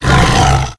c_sibtiger_hit2.wav